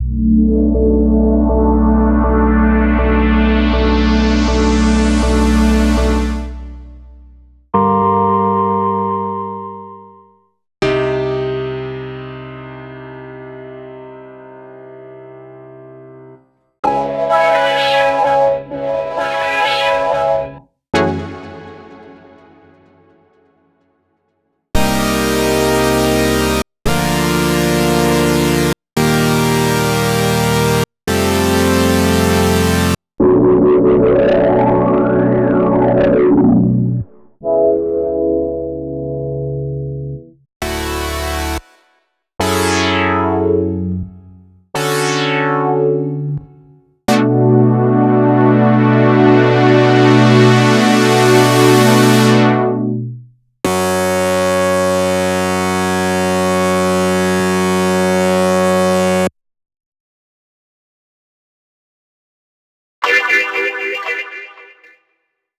CHORDS.wav